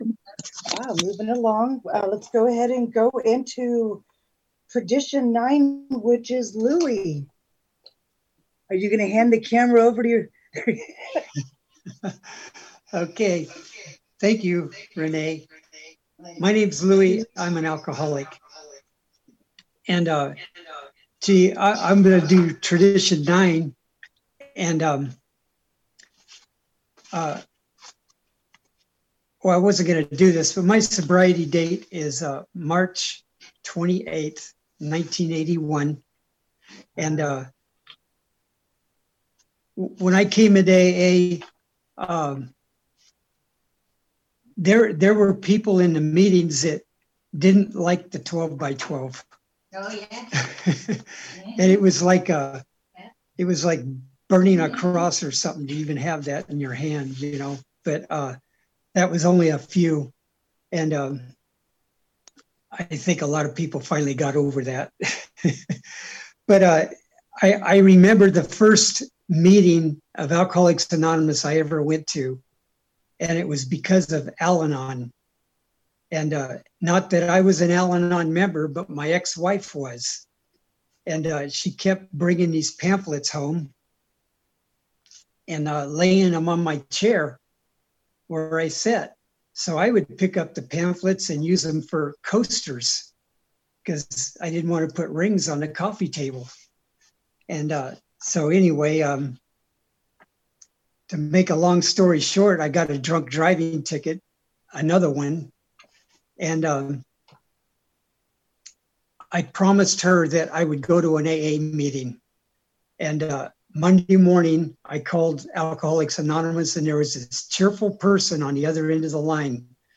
CNIA DISTRICT 40 41 42 AND 43 TRADITIONS WORKSHOP